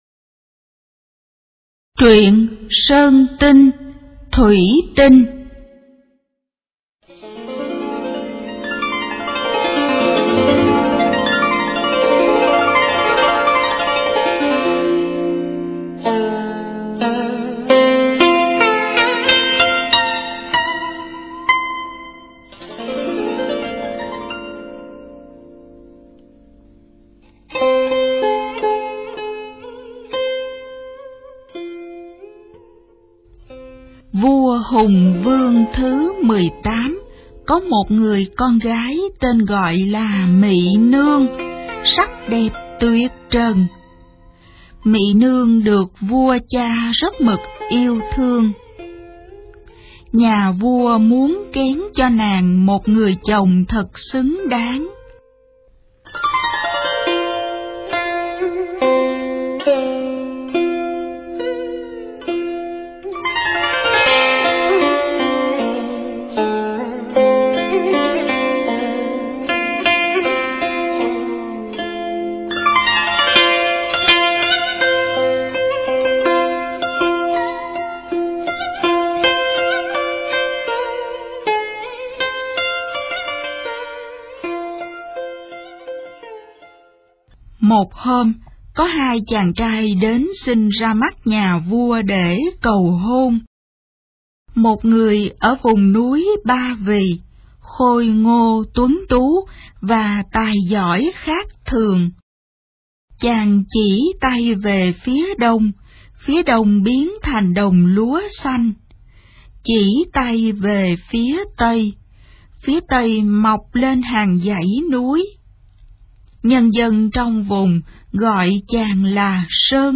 Sách nói | Truyện cổ tích Việt Nam. 04